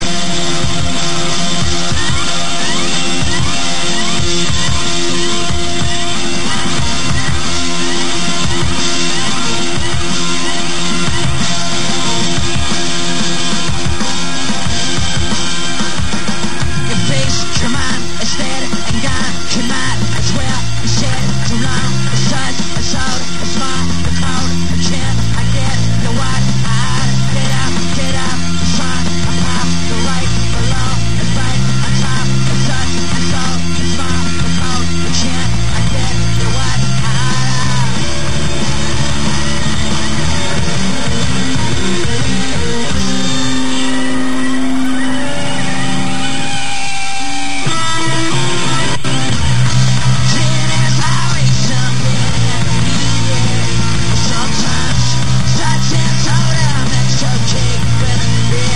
live at Festival do Sudoeste 97